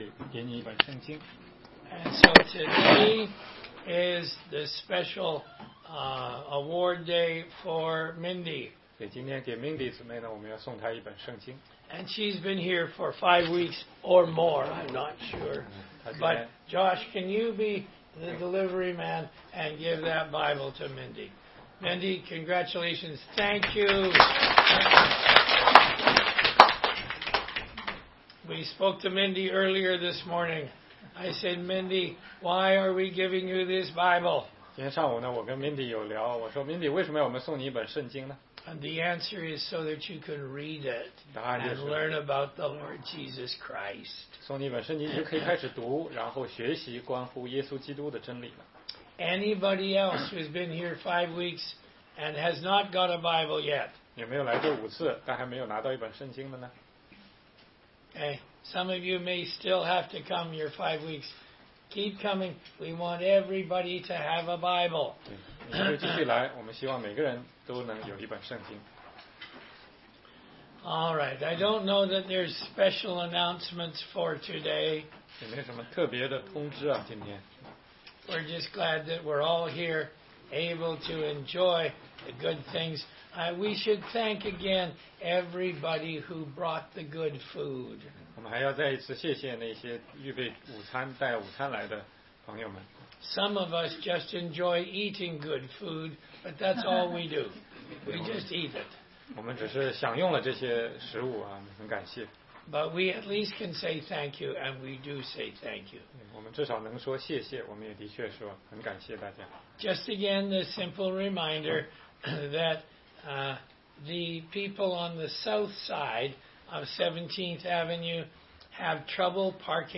16街讲道录音 - 哥林多前书10章6-8节：旷野的警告之一